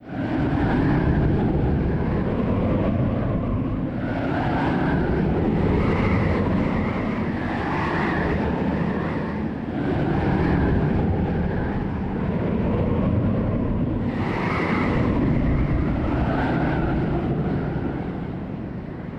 Wind2.wav